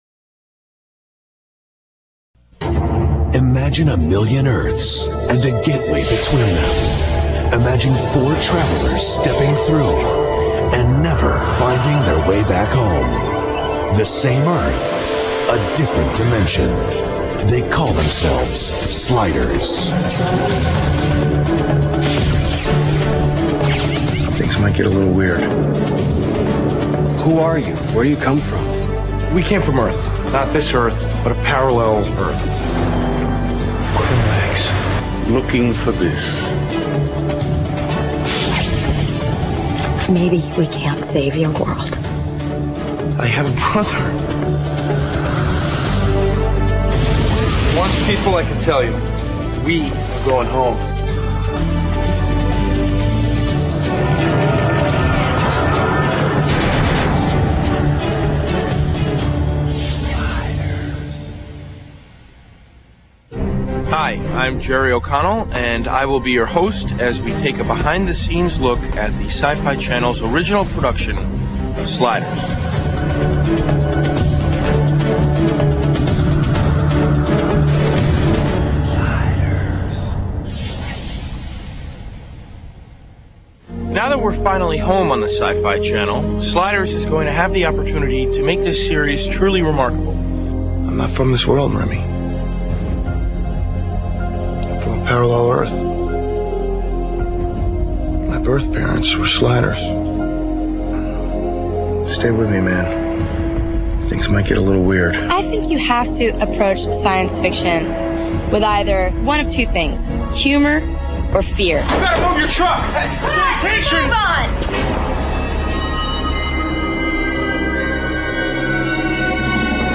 Un document � ne pas manquer sur les coulisses de Sliders, avec notamment les interviews des acteurs de la s�rie (version originale).